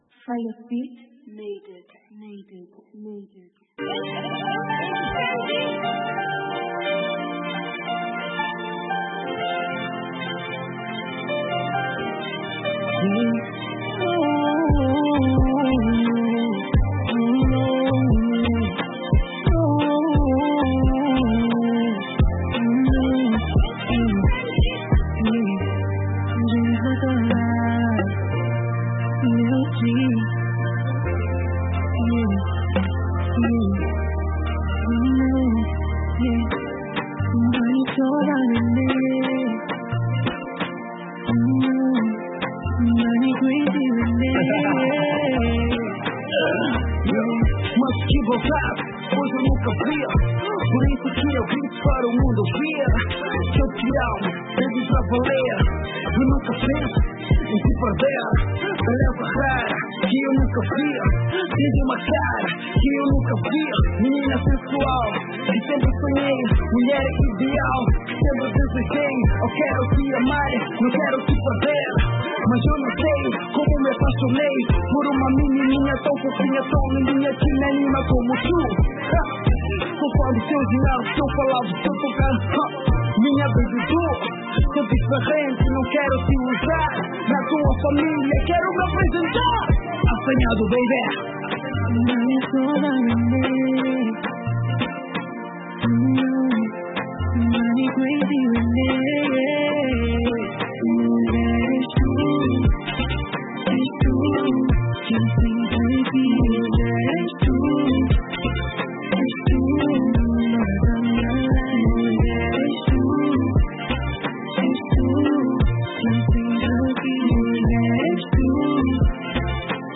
kizomba e afro house